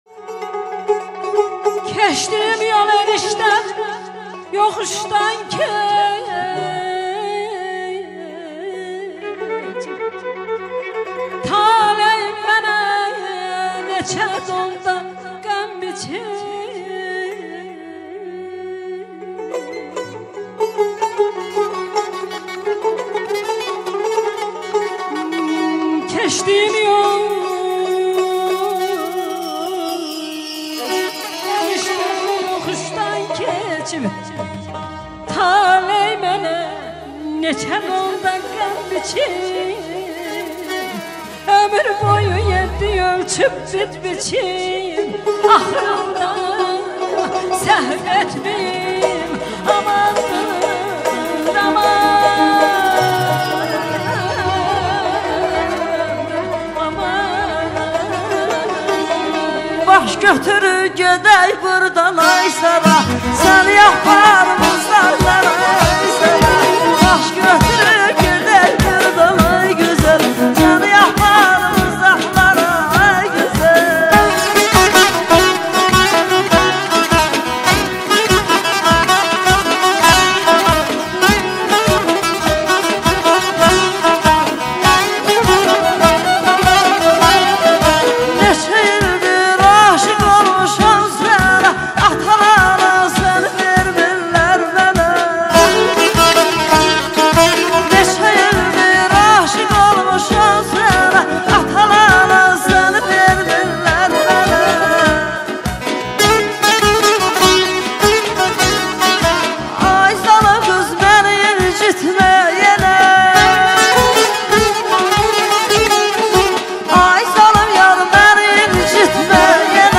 Canlı ifa